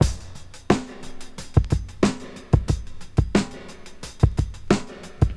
• 90 Bpm Modern Drum Groove B Key.wav
Free drum beat - kick tuned to the B note. Loudest frequency: 1120Hz
90-bpm-modern-drum-groove-b-key-h5D.wav